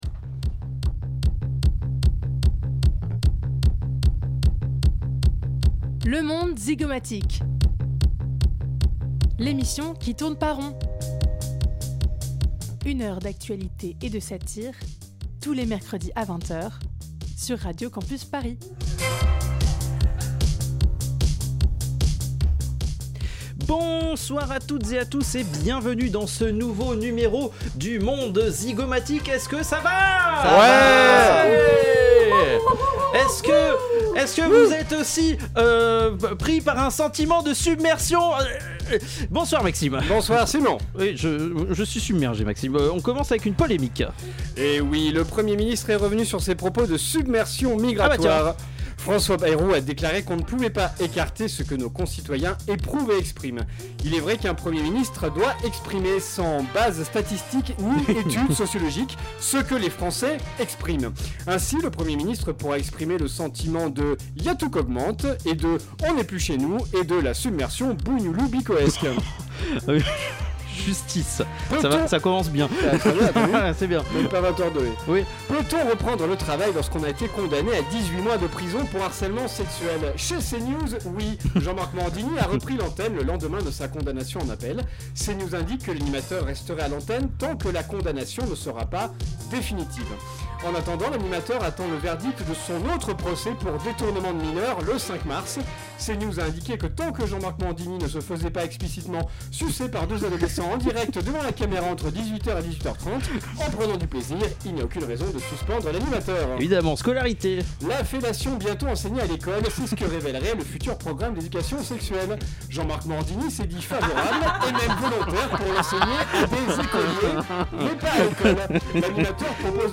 Magazine Société